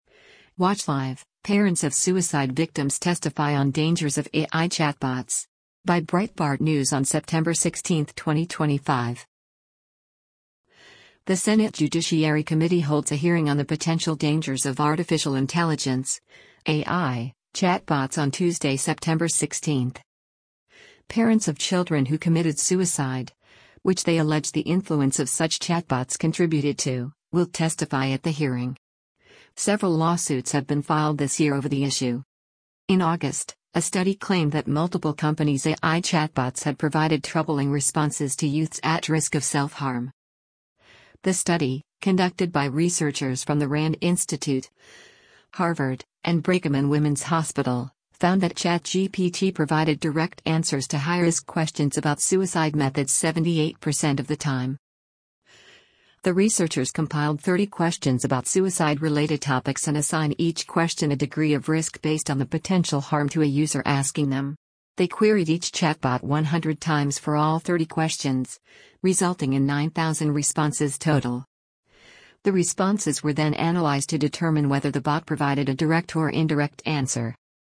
The Senate Judiciary Committee holds a hearing on the potential dangers of artificial intelligence (A.I.) chatbots on Tuesday, September 16.